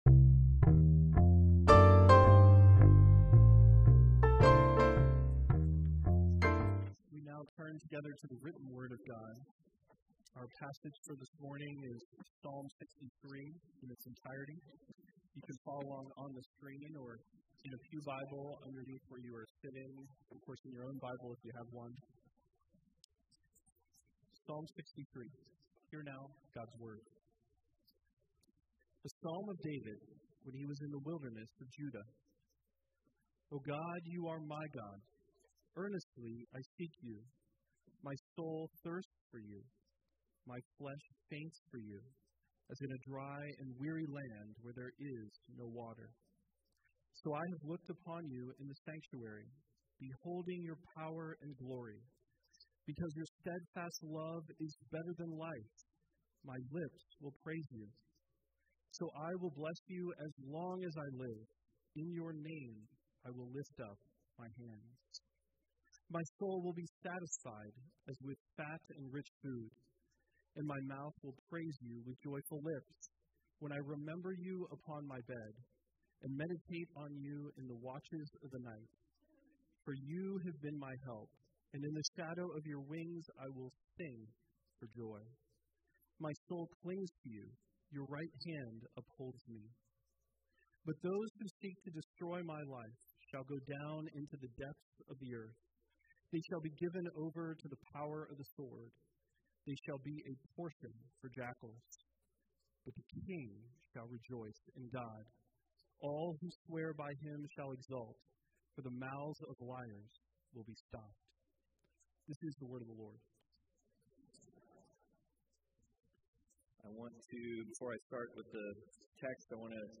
Psalm 63 Service Type: Sunday Worship With God is everlasting joy
NAPC_Sermon_6.23.24.mp3